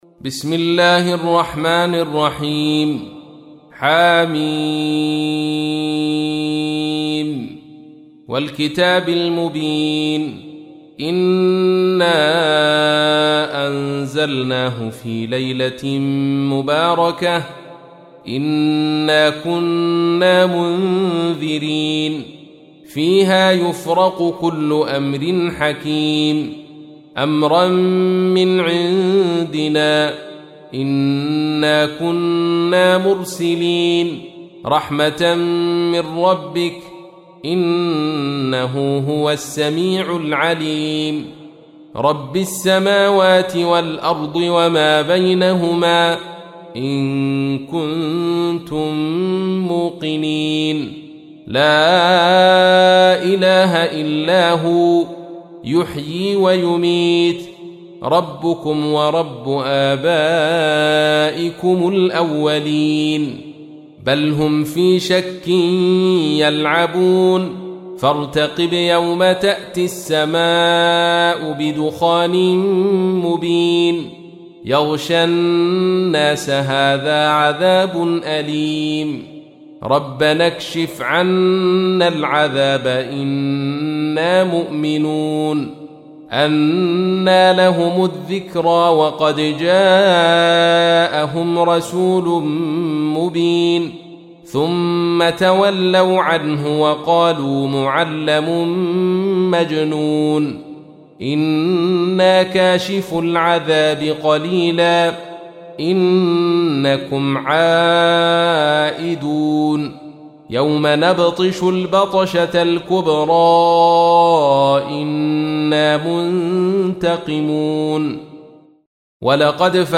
تحميل : 44. سورة الدخان / القارئ عبد الرشيد صوفي / القرآن الكريم / موقع يا حسين